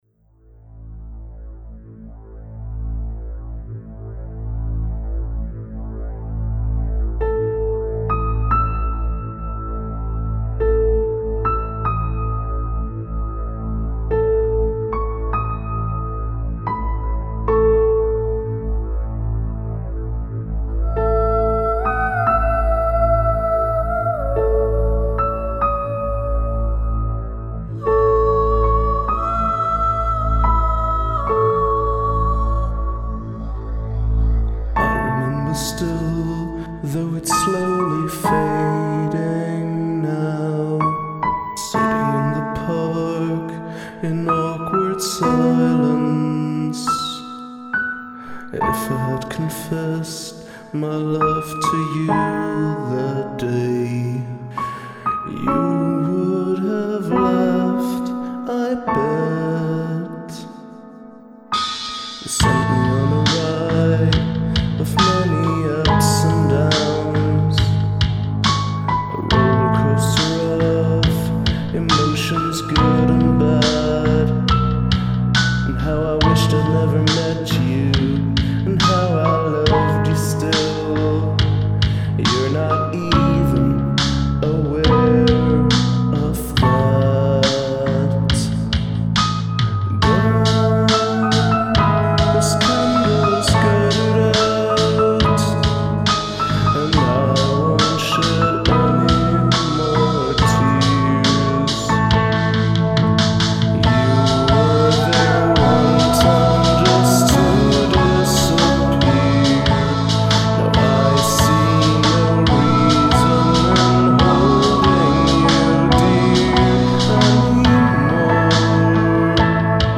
Sampled Vocal Hook